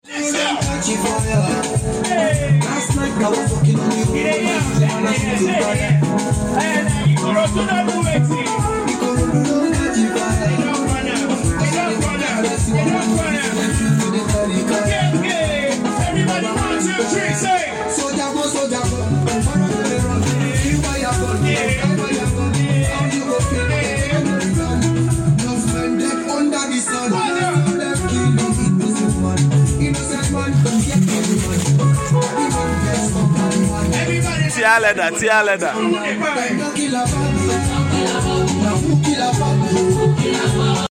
new sets again intro kD with 100coil in and out 15inche sub for kD 315 model